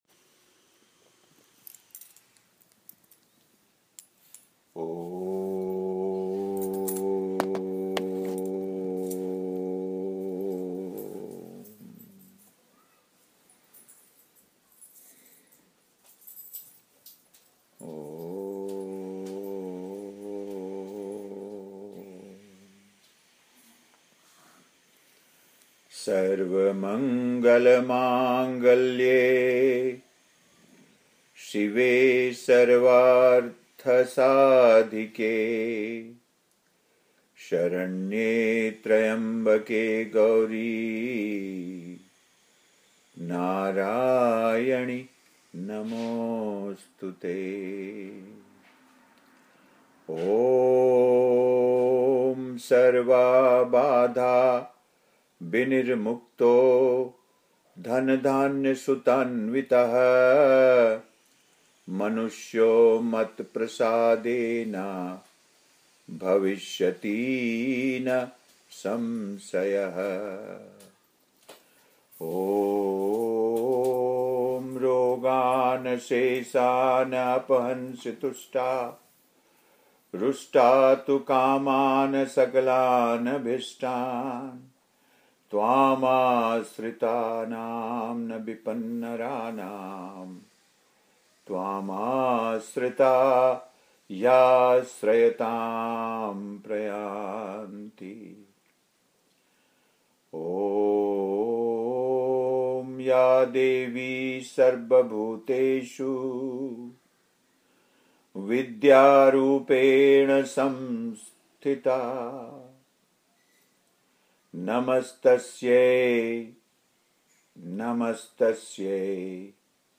Initiation call for e-course Wednesday, July 22, 2015